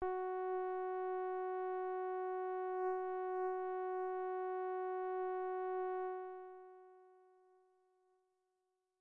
标签： MIDI-速度-96 F4 MIDI音符-66 Oberheim-Xpander的 合成器 单票据 多重采样
声道立体声